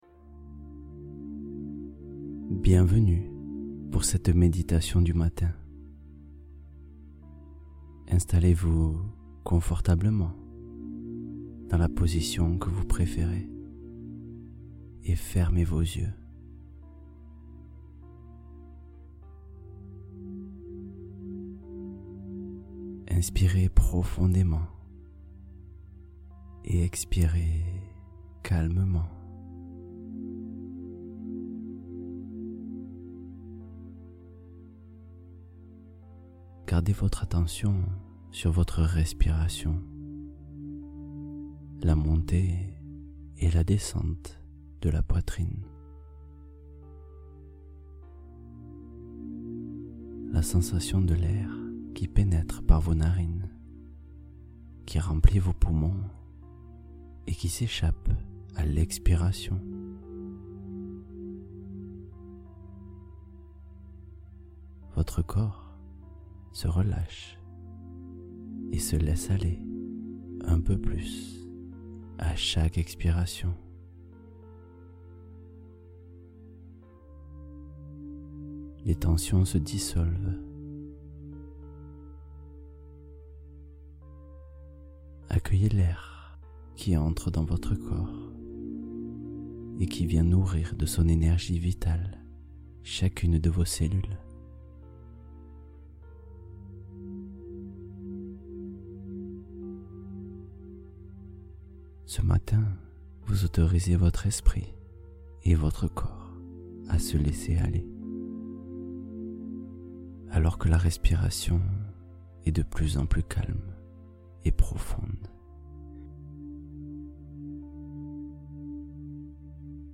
Réveil de Confiance : Séance matinale pour ancrer sa sérénité